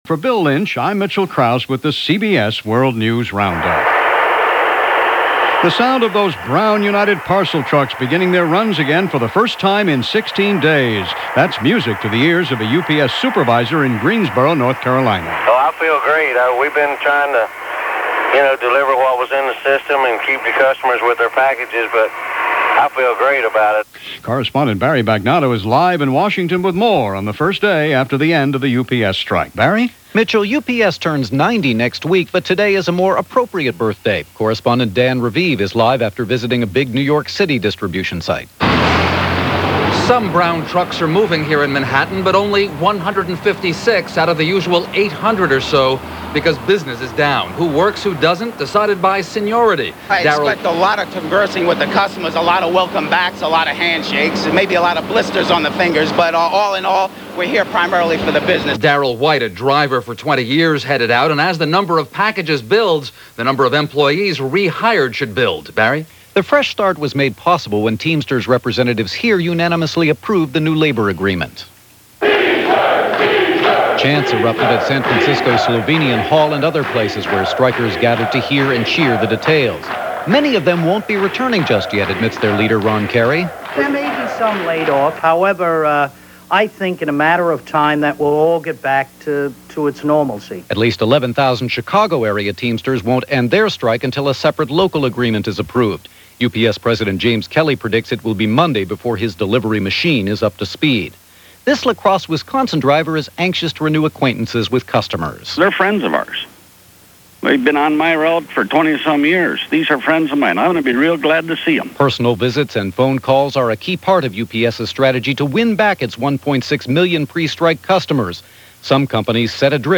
And while the UPS trucks got rolling, that’s a small slice of what happened on this August 20th 1997 as reported on the CBS World News Roundup.